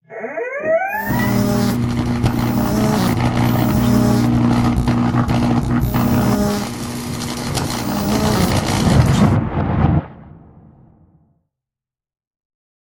shock.ogg.mp3